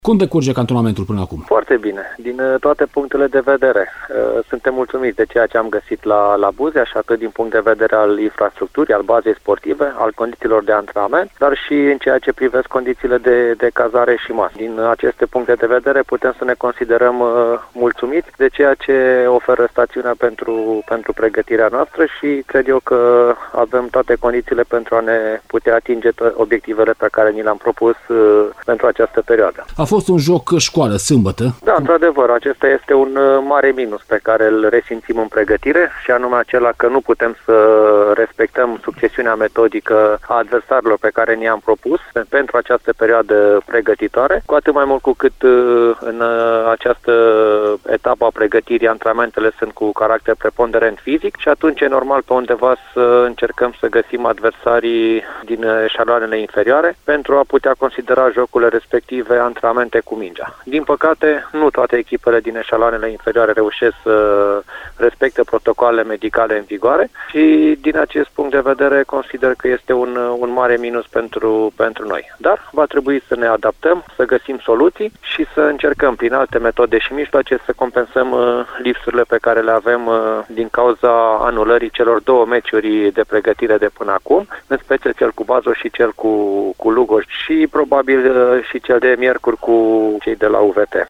Într-un interviu pentru Radio Timișoara